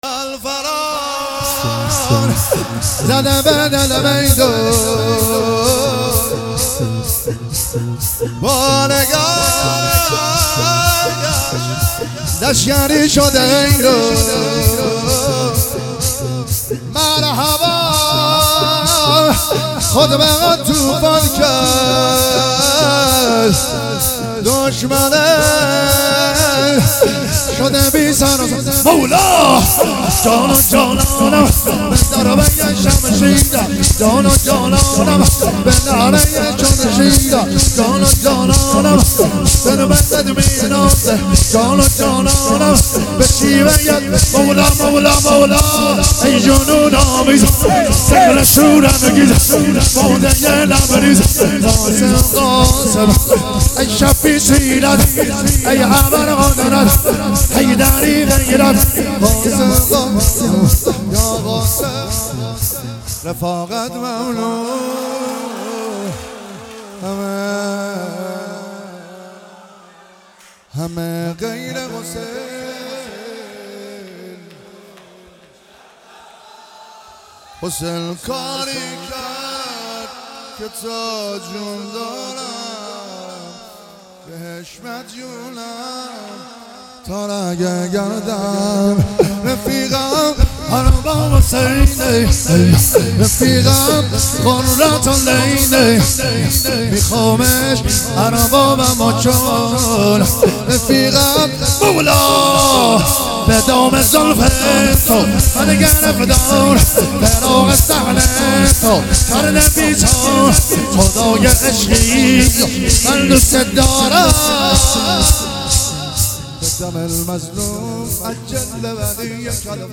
ایام فاطمیه 1399 | هیئت روضه الشهدا دزفول